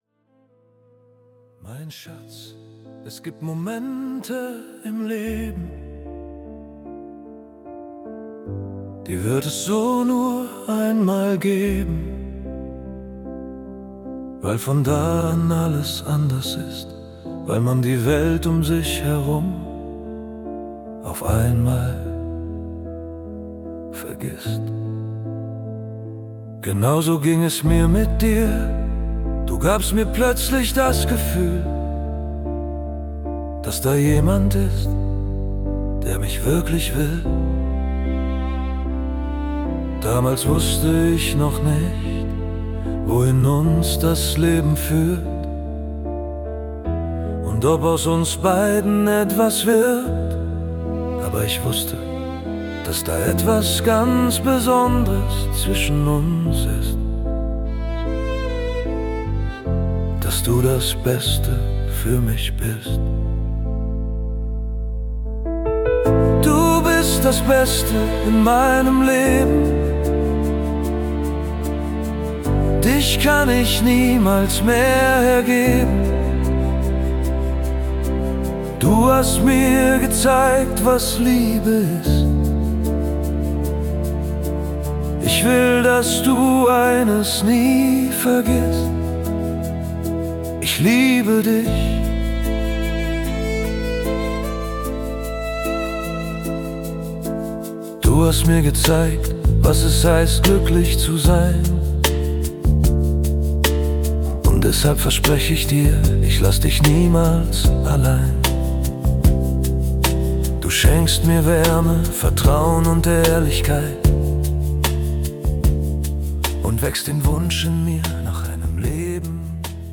(Songwriter-Ballade)